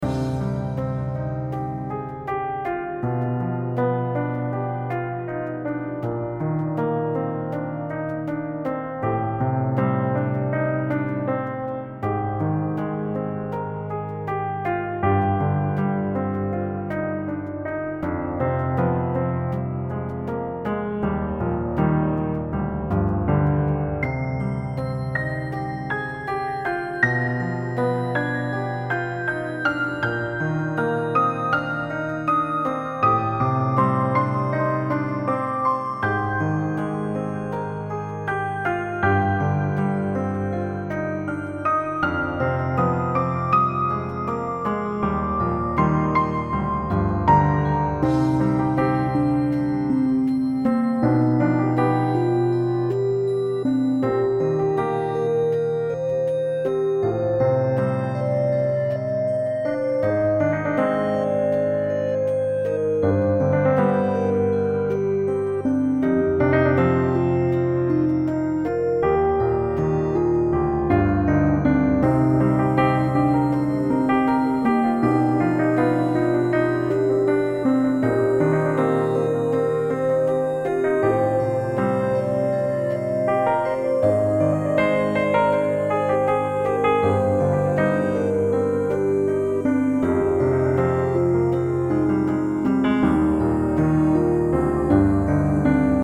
Key Instruments: Piano, Synth, Synth Voice